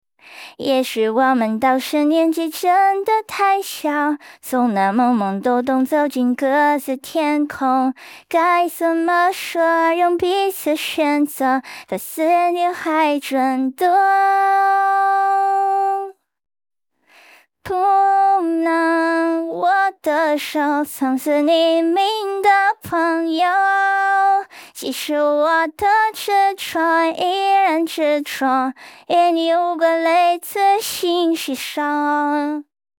唱歌表现